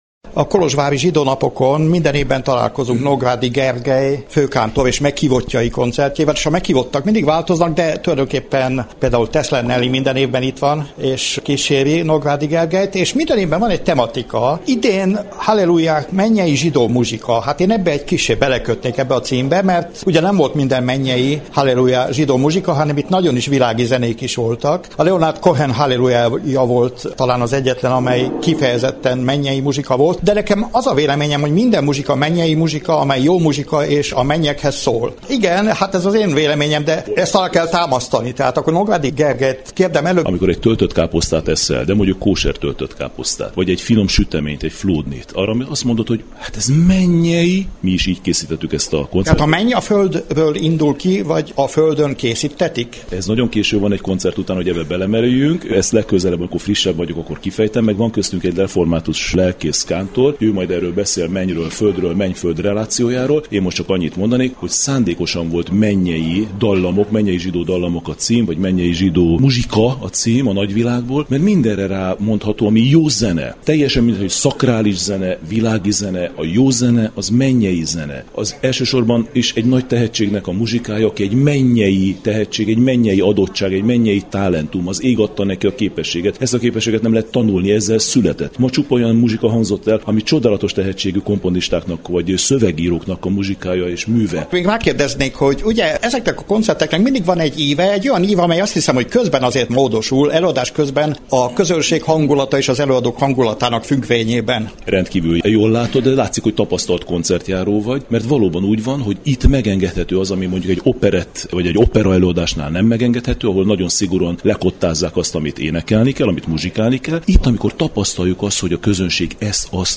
Az eseményt követően Dés Lászlóval készült beszélgetés zárja összeállításunkat.